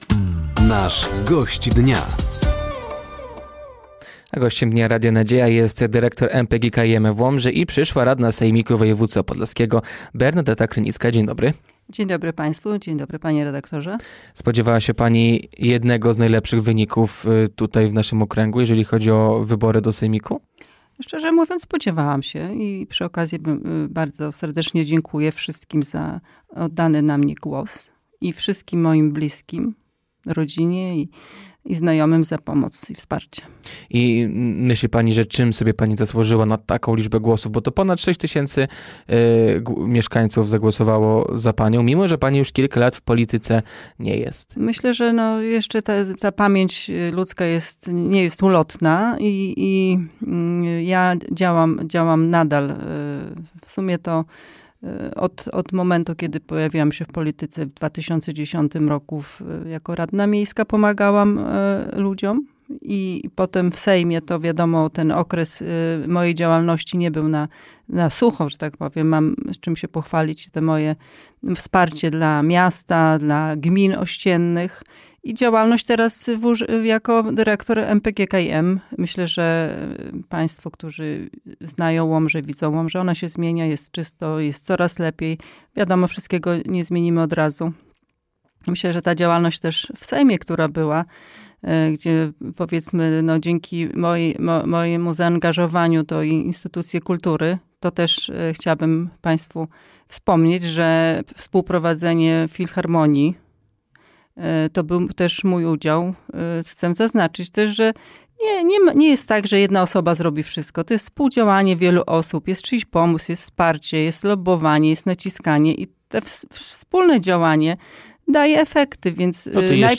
Gościem Dnia Radia Nadzieja była dyrektor MPGKiM w Łomży i nowa radna sejmiku województwa podlaskiego Bernadeta Krynicka. Tematem rozmowy był między innymi wynik wyborów oraz plany nowej radnej na swoją działalność w sejmiku.